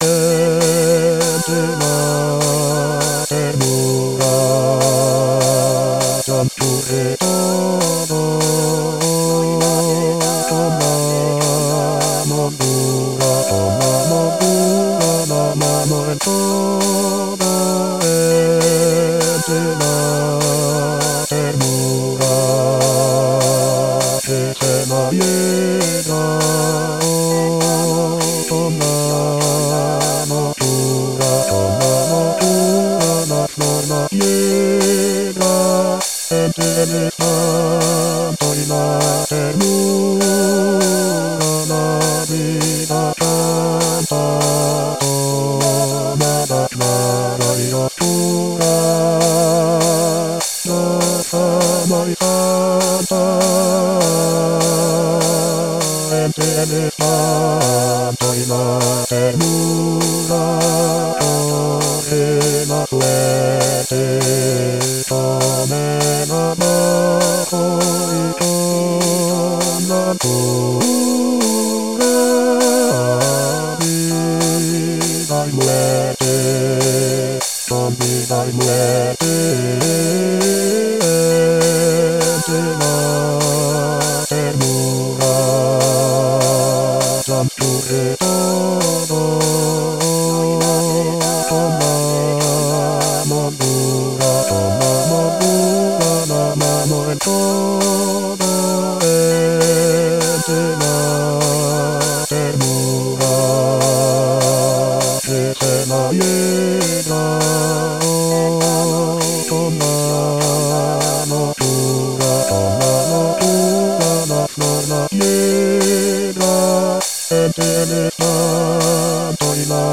Bass Bass 1